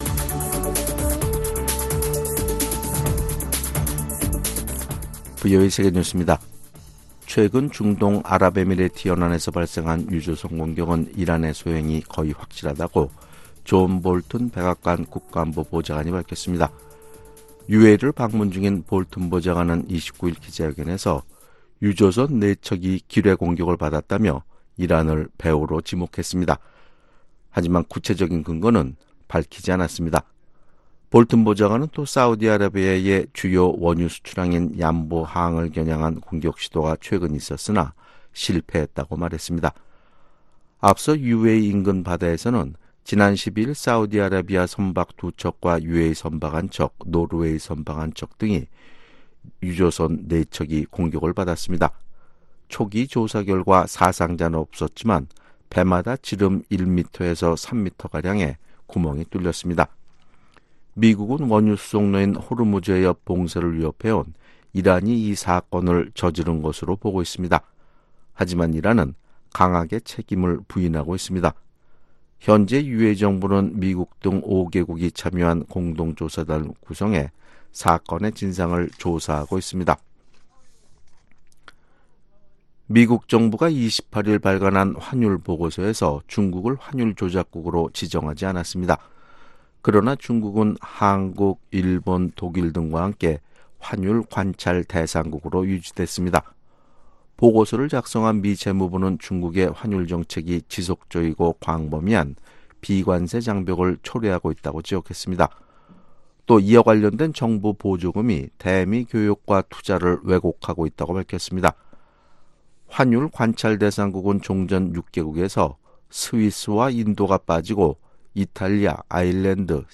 VOA 한국어 아침 뉴스 프로그램 '워싱턴 뉴스 광장' 2019년 5월 30일 방송입니다. 국무부는 북한의 대량살상무기(WMD) 프로그램 전체가 유엔 안보리 결의에 위배된다고 지적했습니다. 스위스가 북한을 지원하는 ‘4개년 전략’을 추진 중이라고 밝혔습니다.